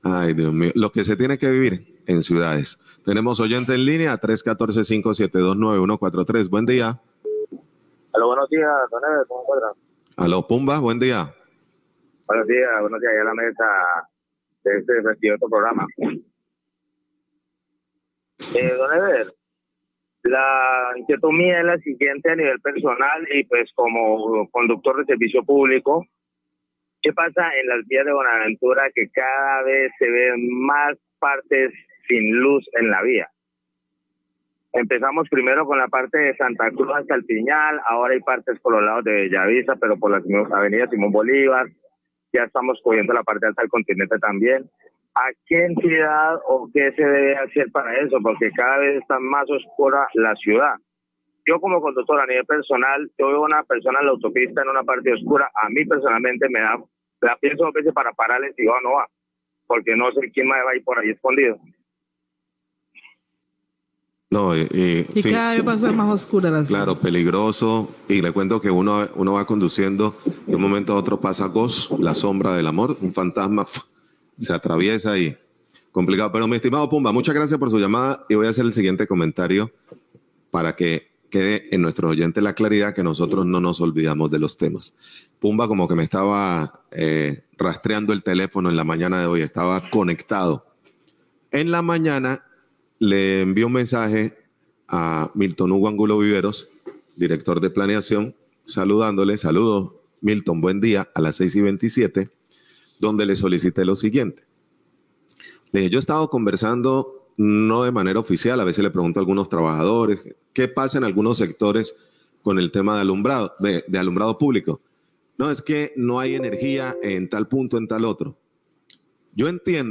Locutor hace interrogante sobre servicio energía para algunos puntos de alumbrado público
Radio